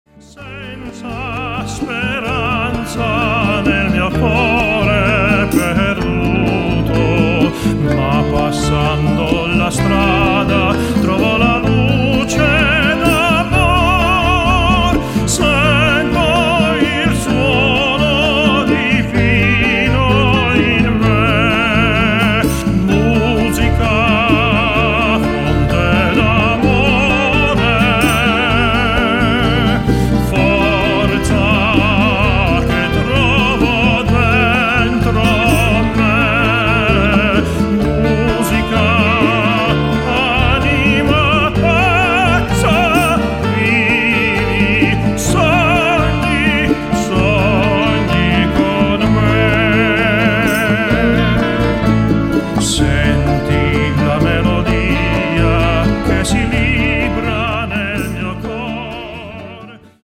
Grandioser Gesang